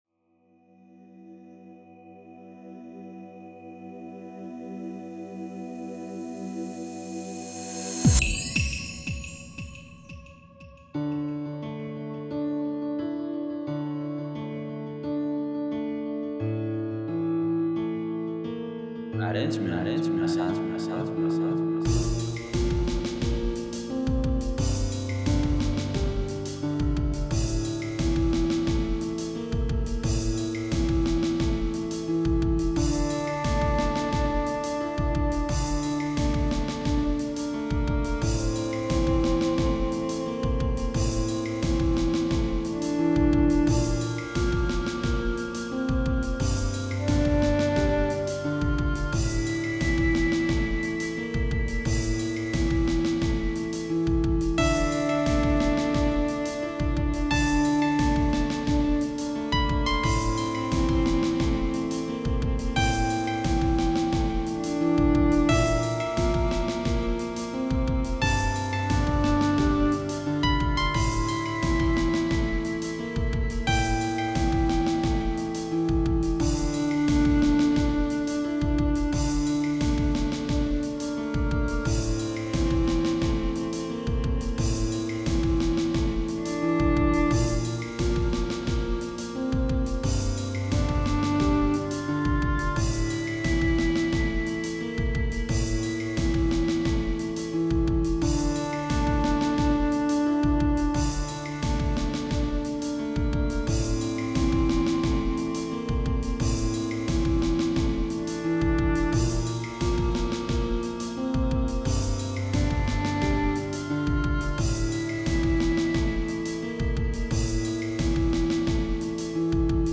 بیت غمگین
ساز زده :گیتار